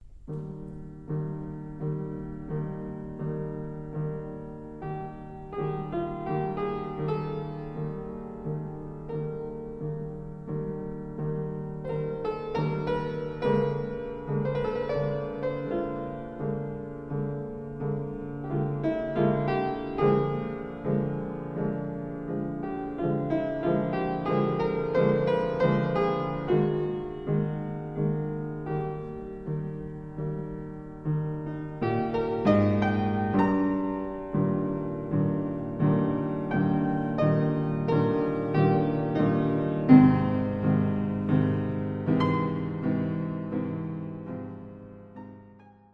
op een historisch instrument.